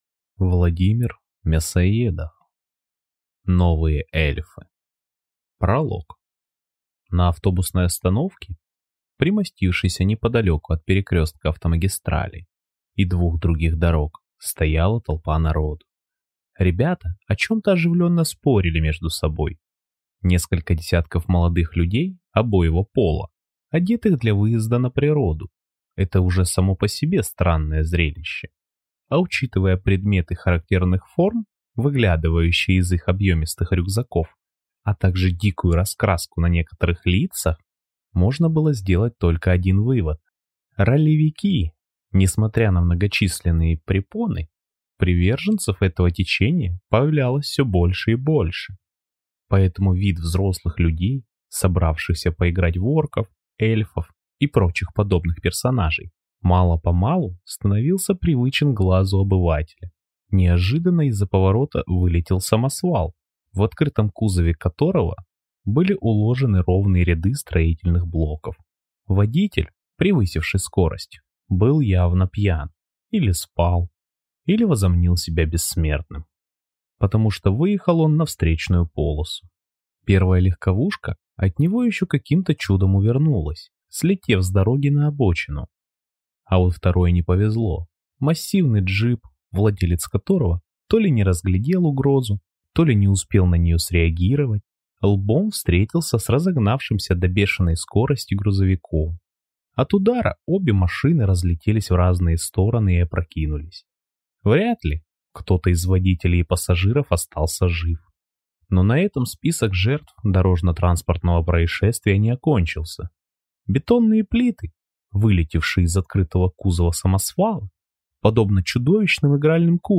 Аудиокнига Новые эльфы | Библиотека аудиокниг